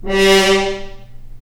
Index of /90_sSampleCDs/Roland L-CD702/VOL-2/BRS_F.Horns FX+/BRS_FHns Mutes